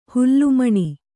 ♪ hullu maṇi